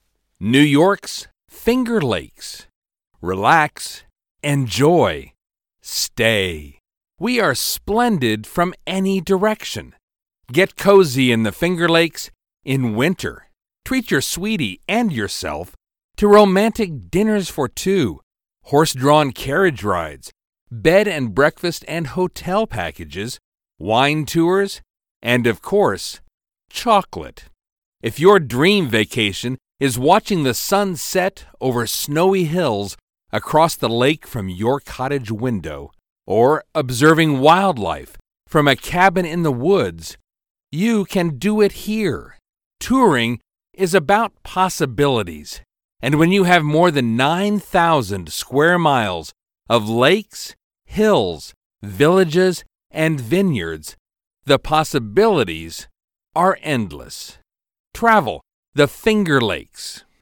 Male
Adult (30-50), Older Sound (50+)
Documentary